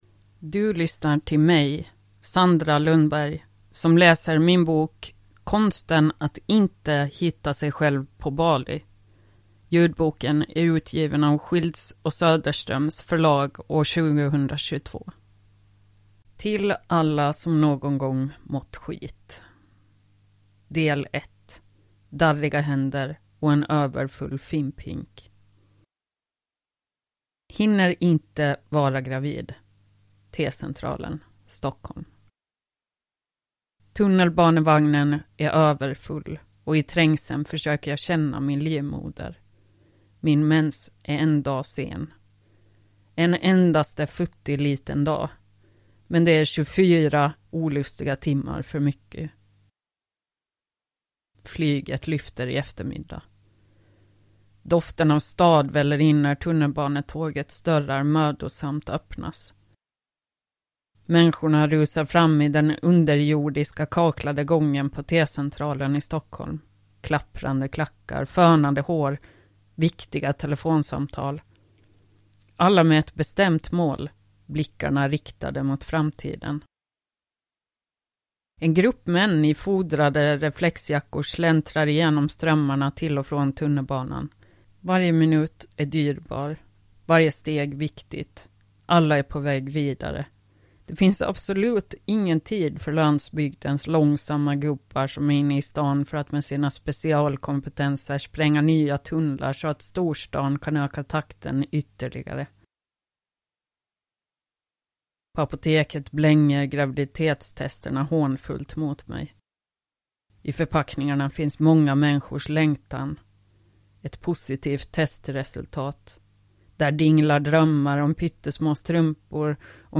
Konsten att inte hitta sig själv på Bali – Ljudbok – Laddas ner